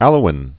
(ălō-ĭn)